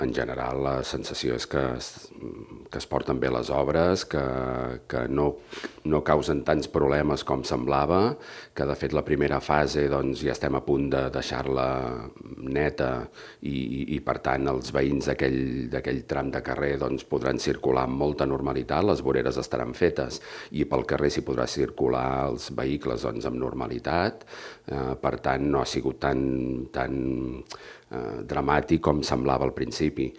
El regidor d'Urbanisme, Xavier Collet, ha destacat el funcionament de la comissió de seguiment i la informació que es facilita a la ciutadania a través del web municipal i dels correus electrònics.